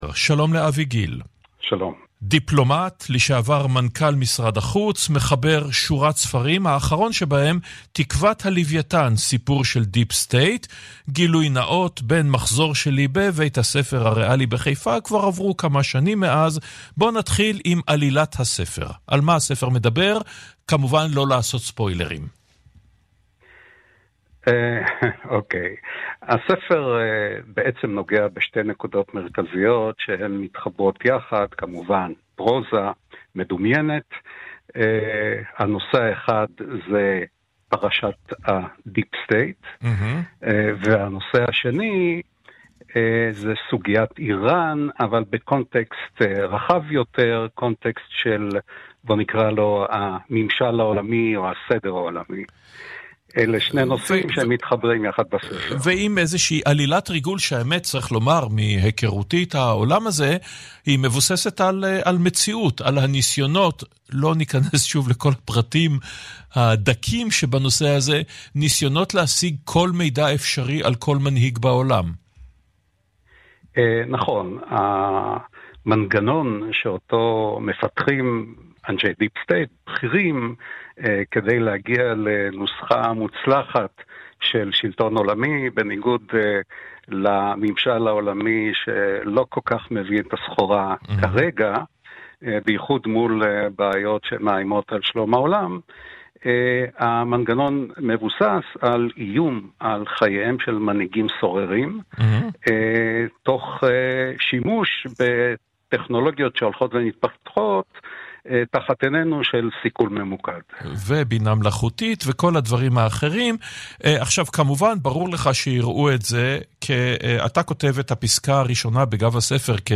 התארחתי בתוכנית "שבת עם אורן נהרי" (כאן-רשת ב') לשיחה על ספרי החדש "תקוות הלווייתן – סיפור של דיפ סטייט".
oren-interview.mp3